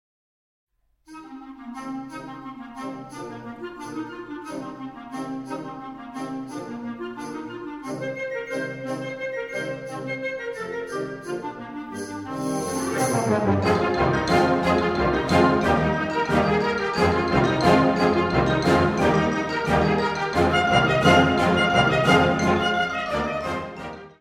Categorie Harmonie/Fanfare/Brass-orkest
Subcategorie Concertmuziek
Bezetting Ha (harmonieorkest)